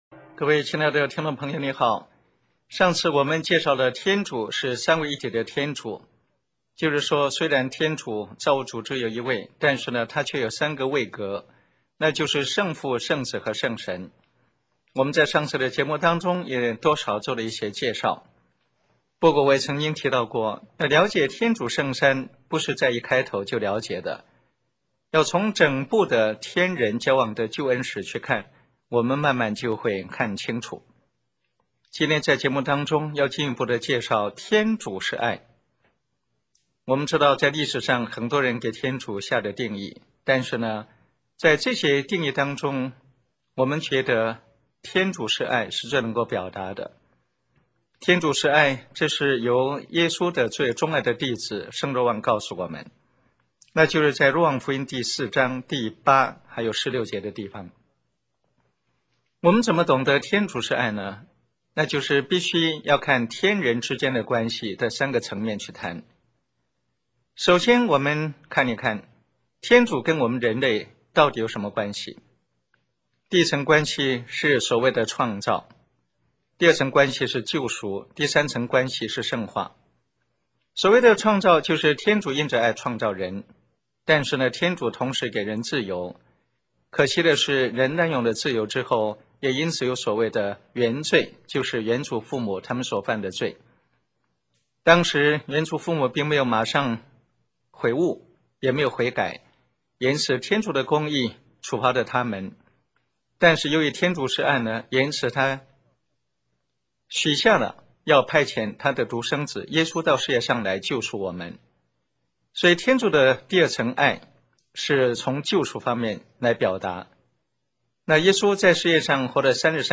以晓畅生动的语调，层次分明地主持这个精采的信仰讲座。
我们重新把电台播放过的节目放在这里，供大家收听。 节目内容简介： 上次我们介绍了天主是三位一体的天主，天主虽然只有一位，但祂却有三个位格，圣父、圣子和圣神。